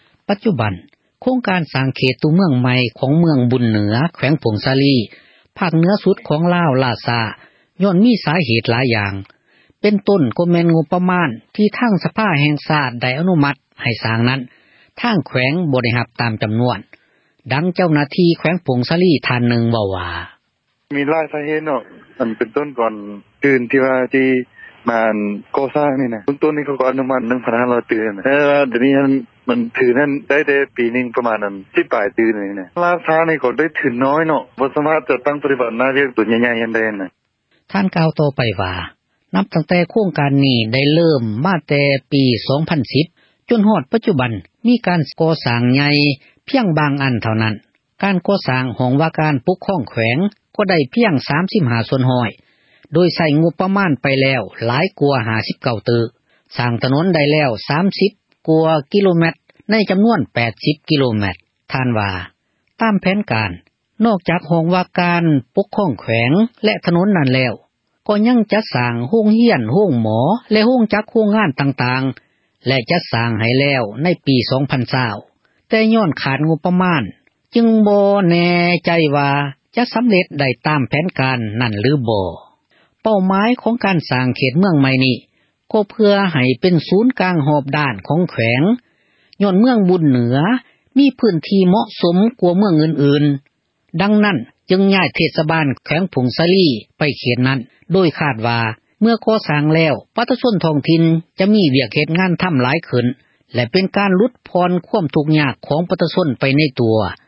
ດັ່ງ ເຈົ້າໜ້າທີ່ ແຂວງ ຜົ້ງສາລີ ເວົ້າວ່າ: